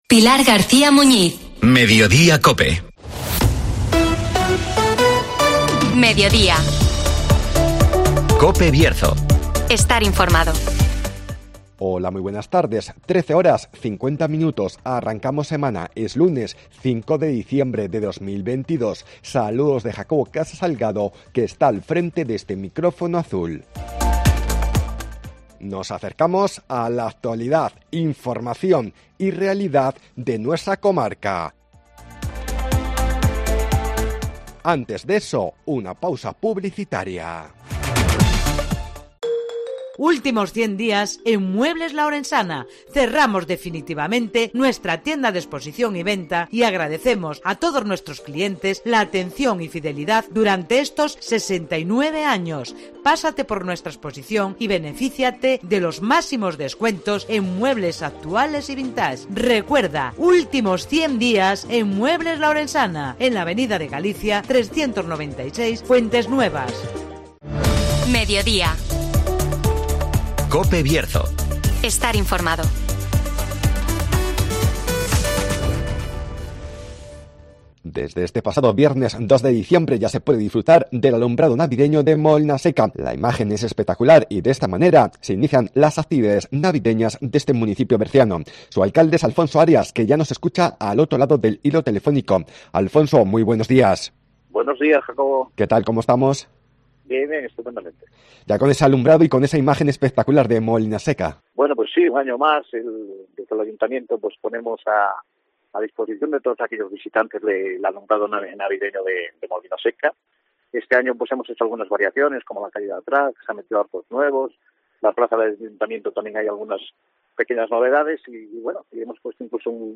En Molinaseca ya es Navidad (Entrevista